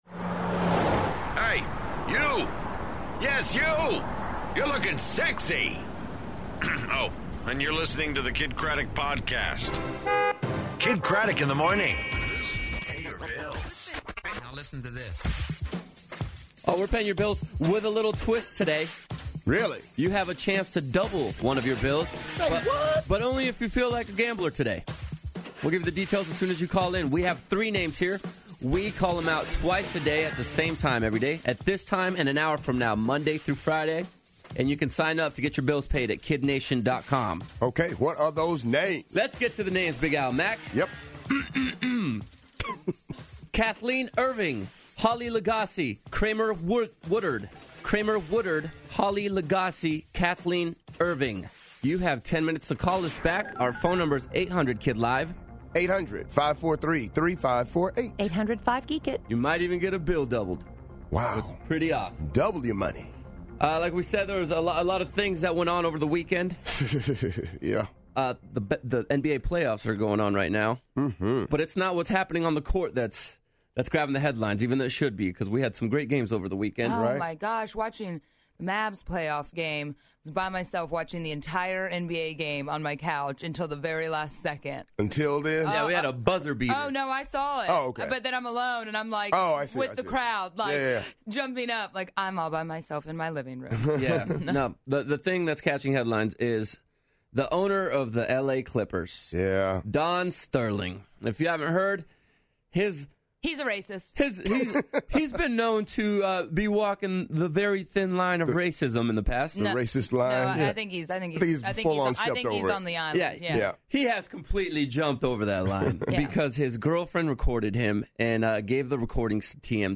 NBA Controversy, Big Break, And A New Group Called Haim Is In Studio!